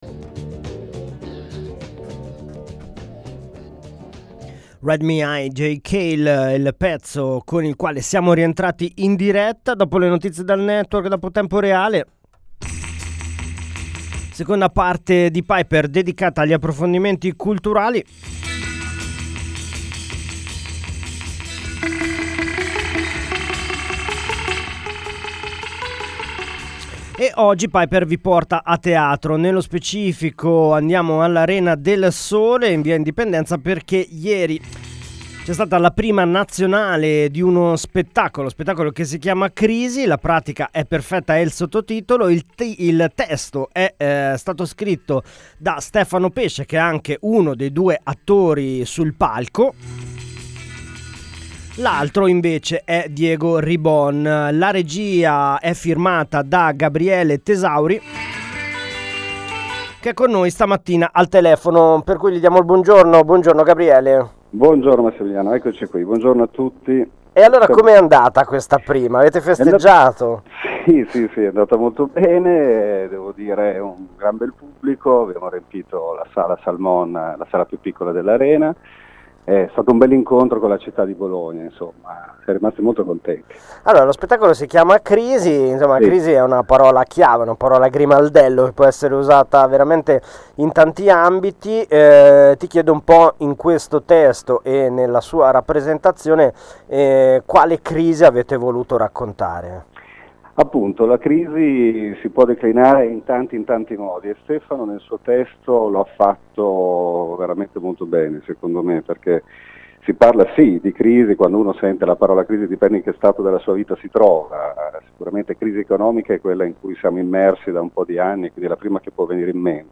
In seguito alla prima nazionale dello spettacolo di Stefano Pesce Crisi, in programma fino al 18 maggio, abbiamo intervistato il regista.